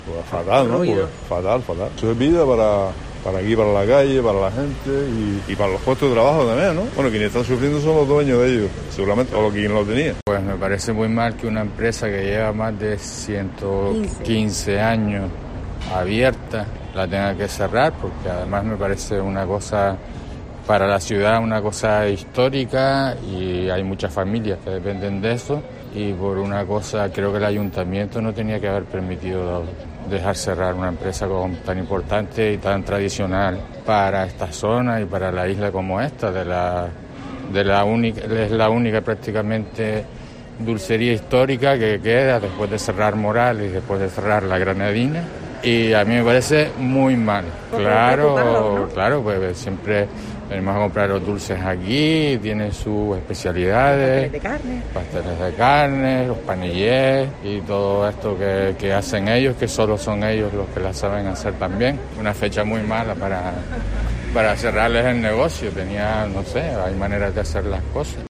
Algunos vecinos valoran el cierre temporal de la Dulcería Parrilla
Los micrófonos de COPE Gran Canaria se han querido acercar a la calle General Bravo, donde se ubica el establecimiento para conocer de primera mano qué es lo que opinan los residentes de la zona y consumidores de la pastelería.